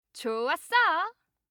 알림음 8_좋았어1-여자.mp3